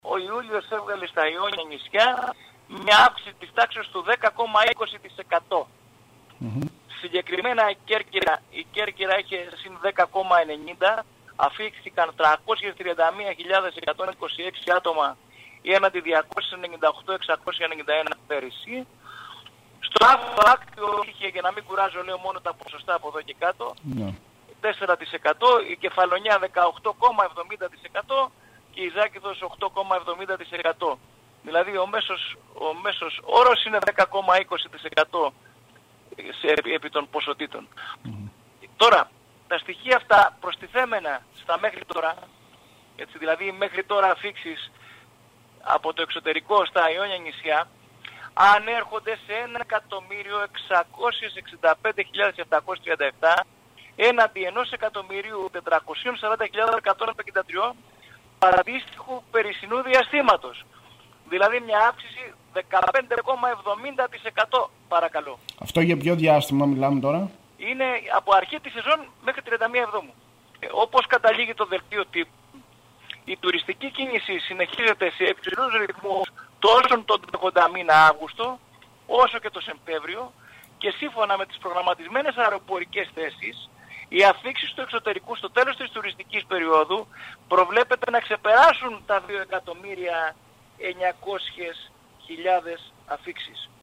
Ιδιαίτερα θετική καταγράφεται η πορεία του τουρισμού των Ιόνιων Νησιών, σύμφωνα με τα στοιχεία των αφίξεων στα αεροδρόμια των νησιών μας και του Ακτίου. Ο Αντιπεριφερειάρχης Τουρισμού Σπύρος Γαλιατσάτος μιλώντας στην ΕΡΤ Κέρκυρας έδωσε τα συγκεκριμένα στοιχεία επισημαίνοντας ότι οι συνολικές αφίξεις στο Ιόνιο ξεπέρασαν από την αρχή της σεζόν  1,6 εκ επισκέπτες ενώ υπολογίζεται ότι στην Περιφέρειά έως το τέλος της σεζόν θα έλθουν 2,9 εκ επισκέπτες που στην ουσία αποτελεί διπλασιασμό των επισκεπτών σε σχέση με την περασμένη δεκαετία.